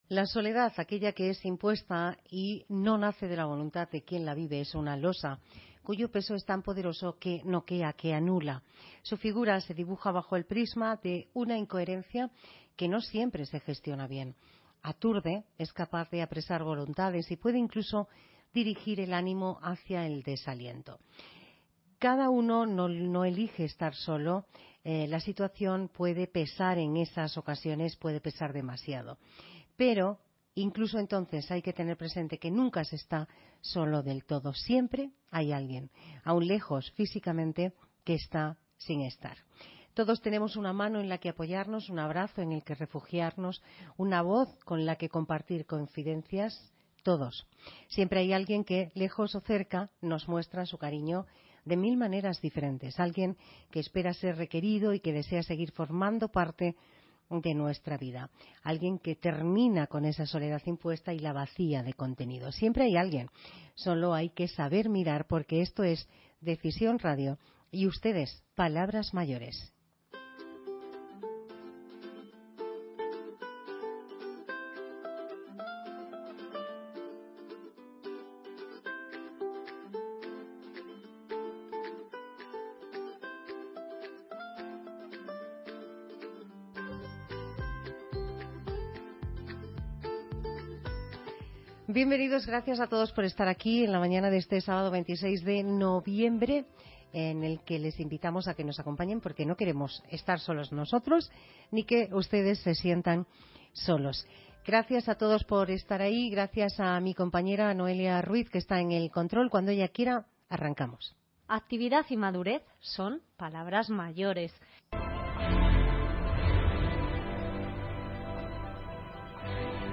Analizamos cómo se trabaja para minimizar los efectos de la soledad no deseada en las personas mayores. Charlamos con profesionales de Ilunion Sociosanitario, y con usuarios de los Centros de Día que gestionan en Madrid, para conocer qué efectos tiene en la salud esa emoción tan subjetiva y cómo trabajan los profesionales de atención a los mayores.